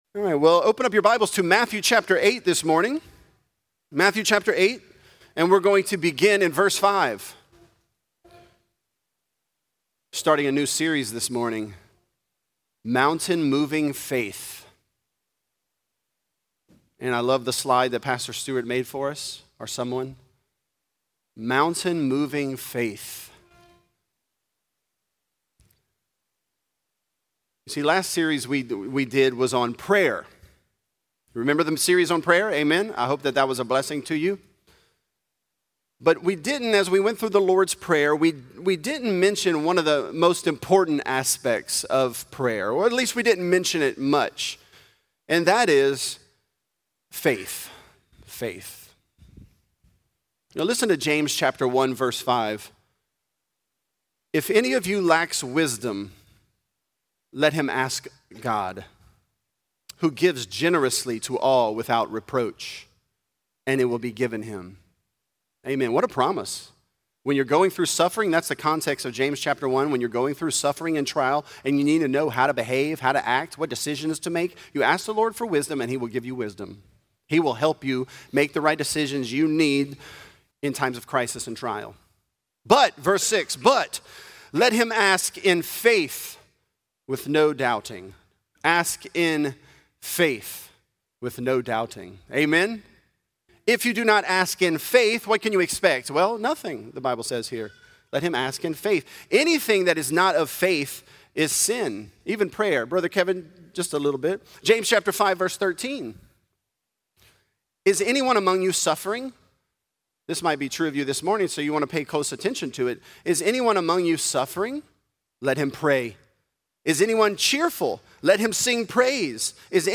Mountain Moving Faith: The Centurion | Lafayette - Sermon (Matthew 8)